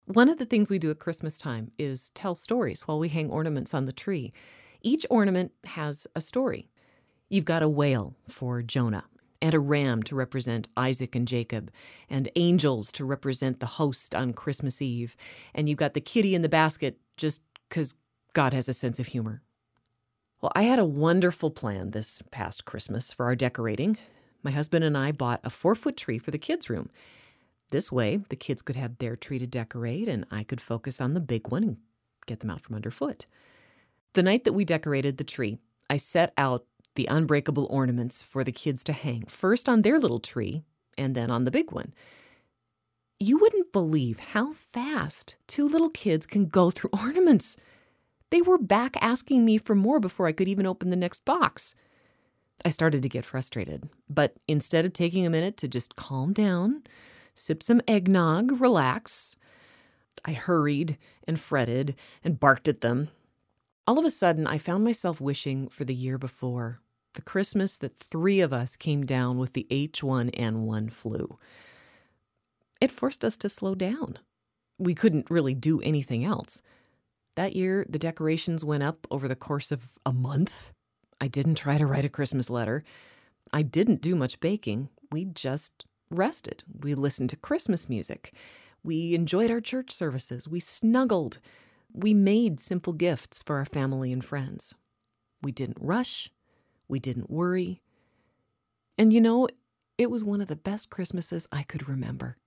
Female
Adult (30-50), Older Sound (50+)
Friendly, Smart, Natural, Credible ~
Narration
Commercial Montage
Studio Quality Sample